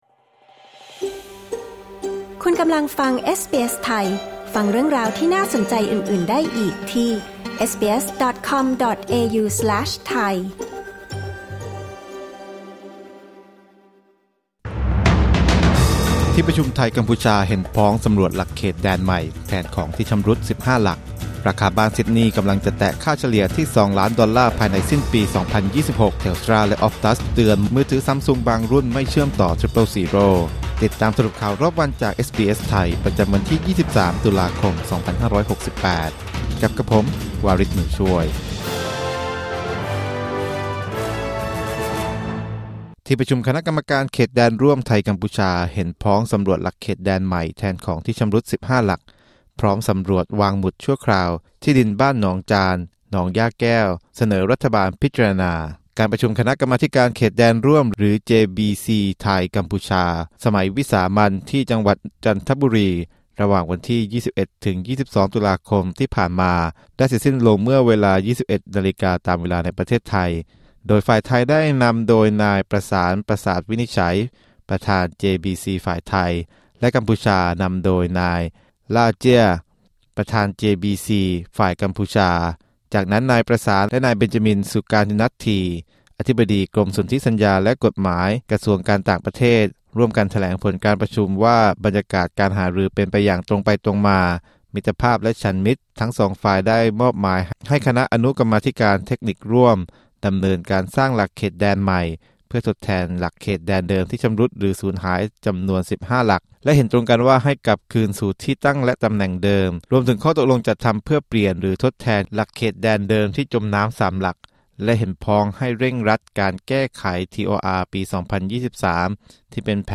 สรุปข่าวรอบวัน 23 ตุลาคม 2568